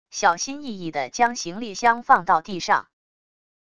小心翼翼的将行李箱放到地上wav音频